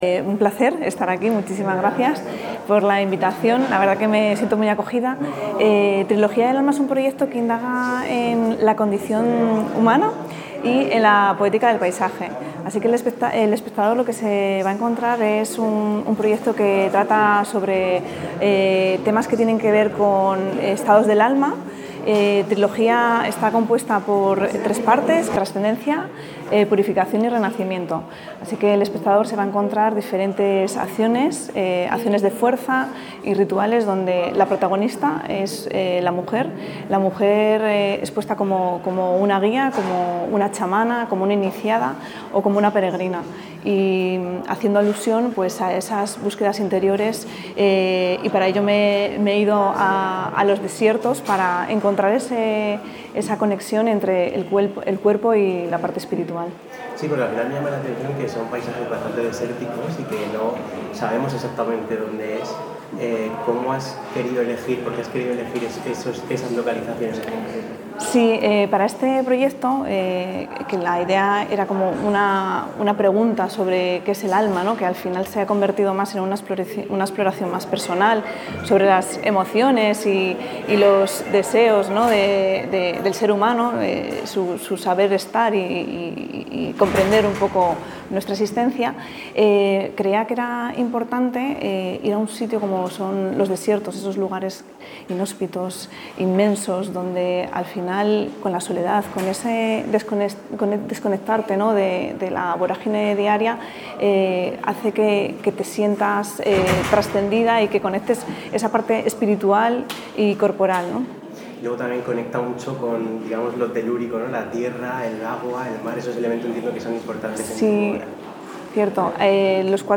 Presentación de la exposición 'Trilogía del alma', en la Sala Domus del Pórtico